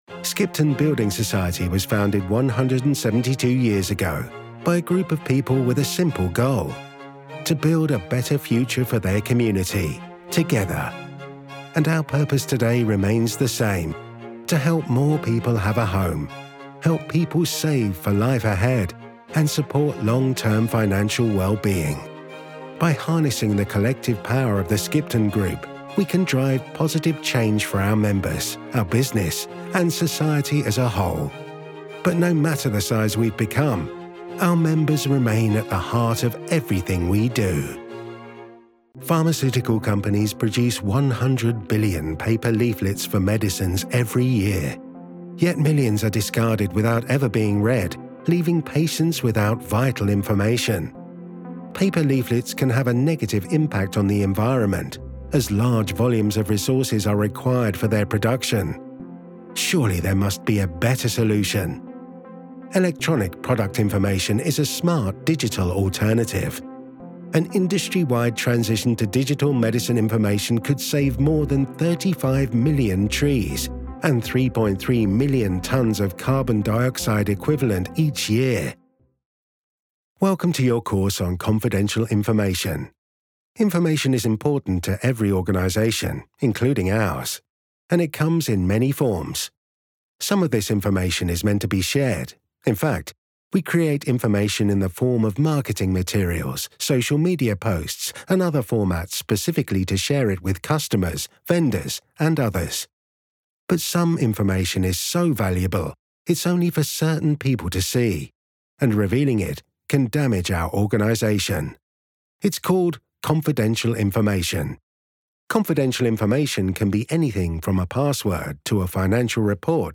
Inglés (Británico)
Profundo, Natural, Accesible, Amable, Cálida
E-learning